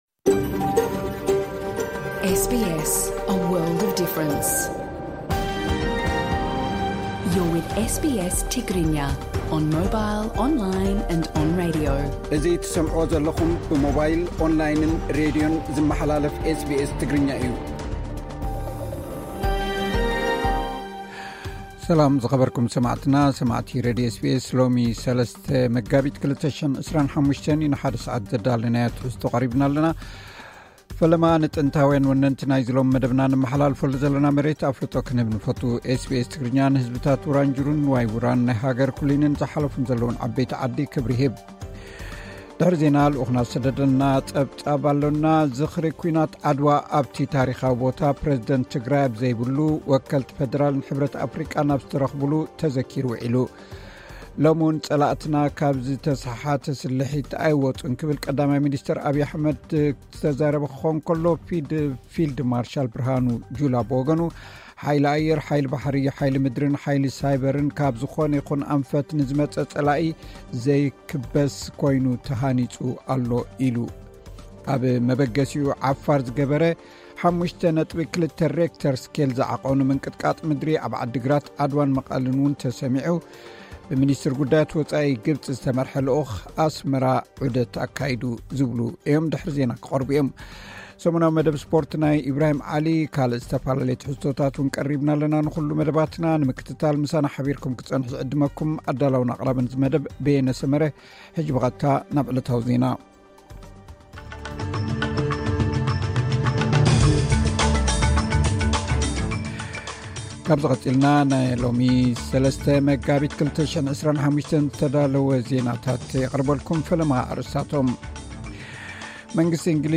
ዕለታዊ ዜና ኤስ ቢ ኤስ ትግርኛ (03 መጋቢት 2025)